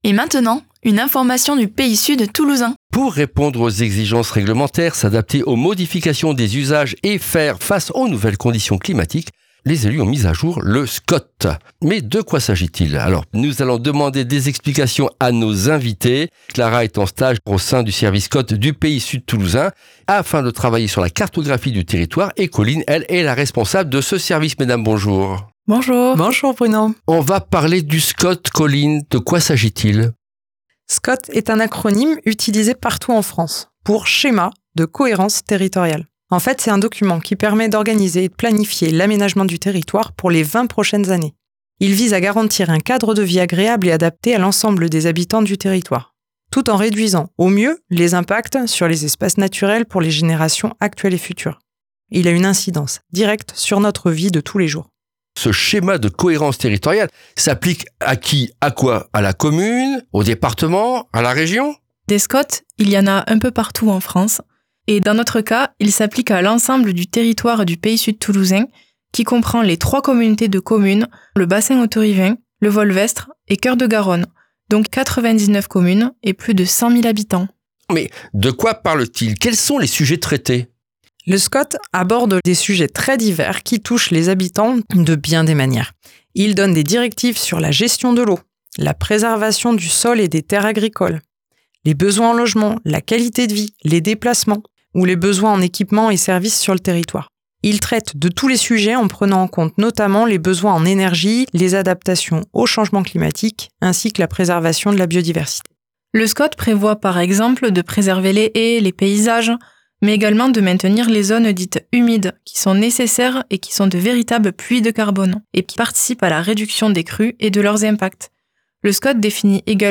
Retrouvez ici les brèves capsules radiophoniques de 5 min qui  servent d’introduction